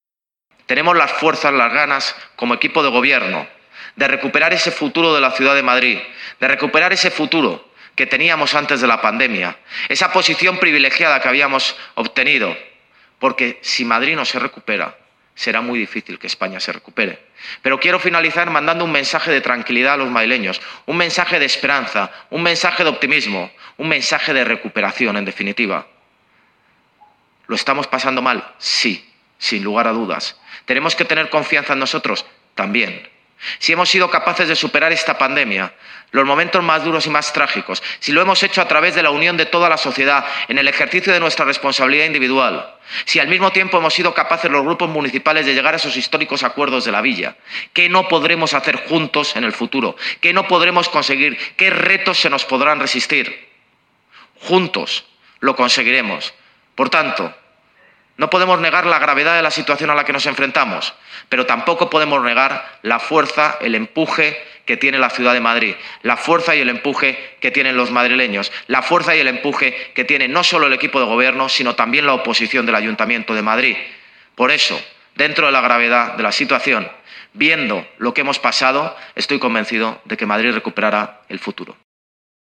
Durante los Desayunos Madrid de Europa Press
Nueva ventana:Declaraciones del alcalde de Madrid, José Luis Martínez-Almeida, sobre la recuperación del futuro de Madrid